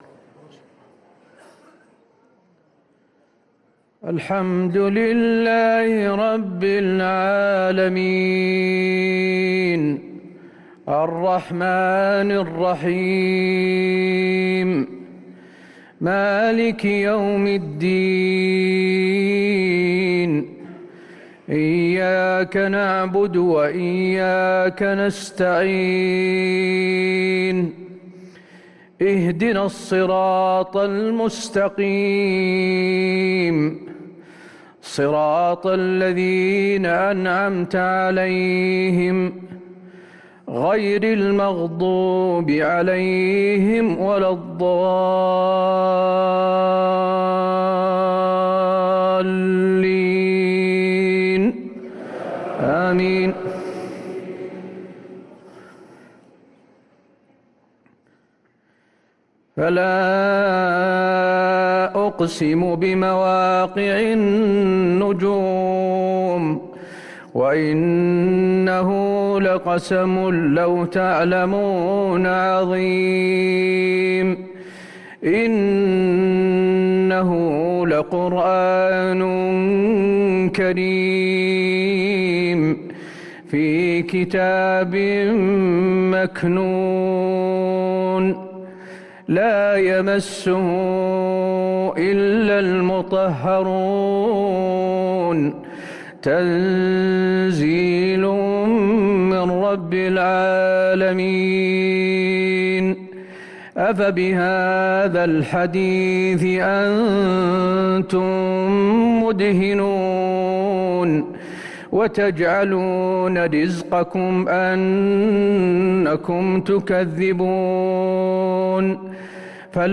صلاة العشاء للقارئ حسين آل الشيخ 4 رمضان 1444 هـ
تِلَاوَات الْحَرَمَيْن .